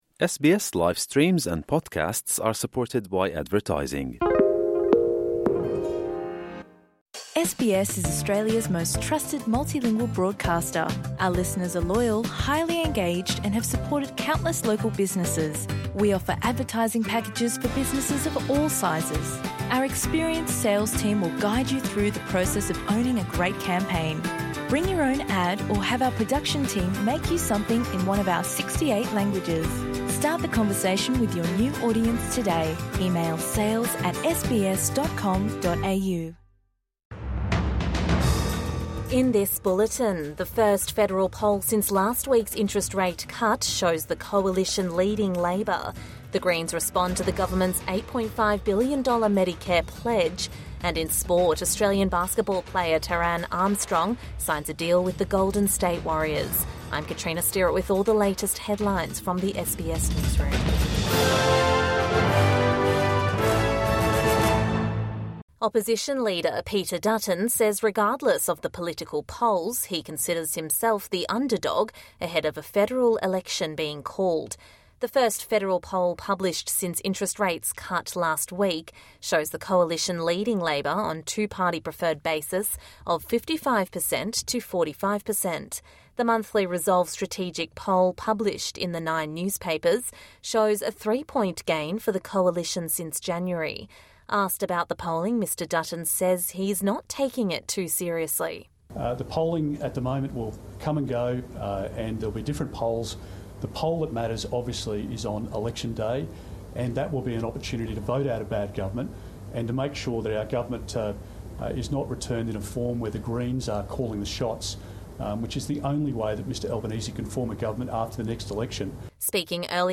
Evening News Bulletin 24 February 2025